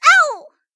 Worms speechbanks
ow1.wav